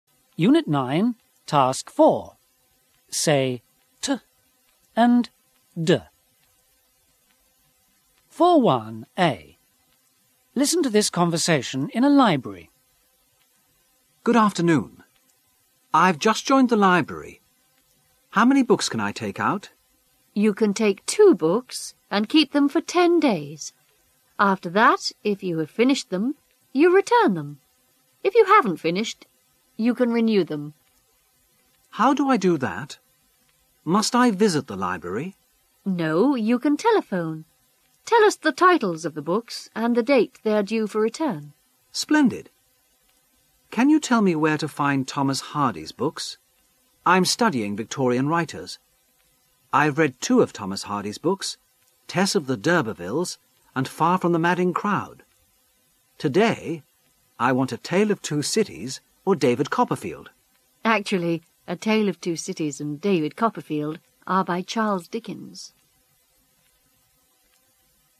SE Unit 9 dialogue t-d.mp3